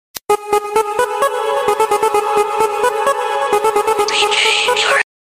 children are playing with beautiful sound effects free download